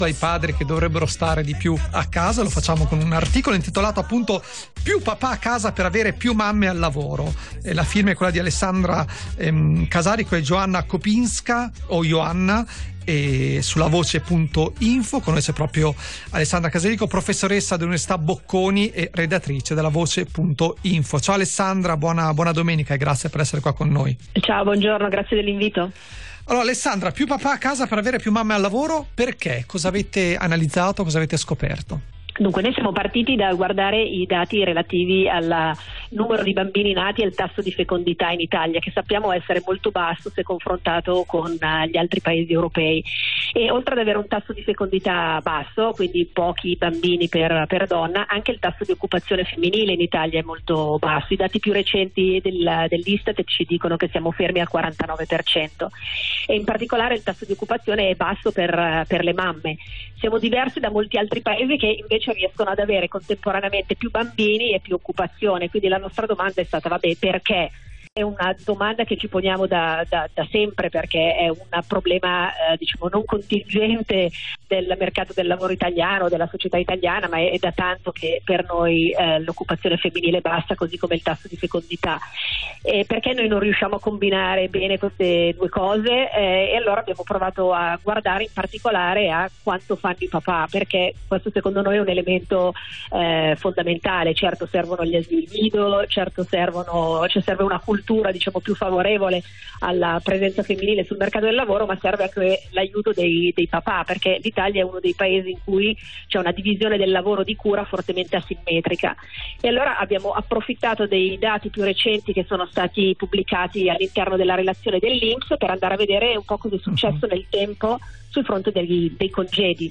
Di seguito l’intervista, qui invece il link alla puntata intera.